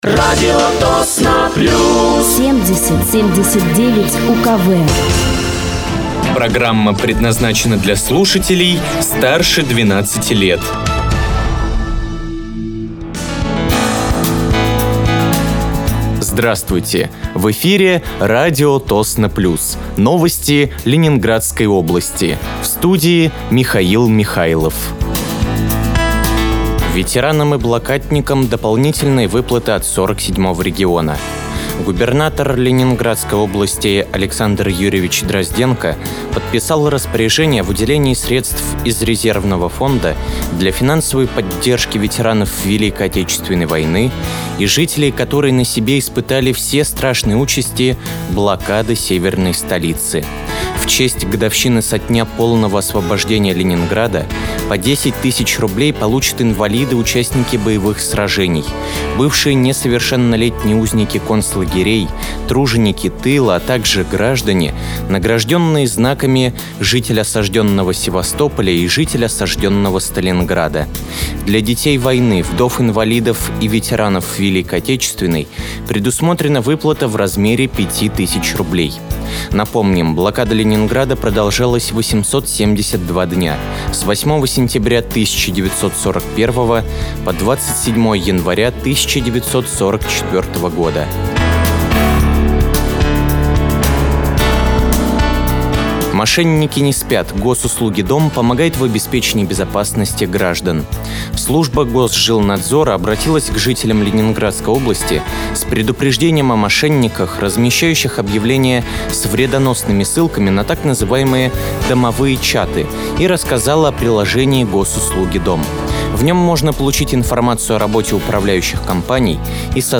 Вы слушаете новости Ленинградской области от 20.01.2025 на радиоканале «Радио Тосно плюс».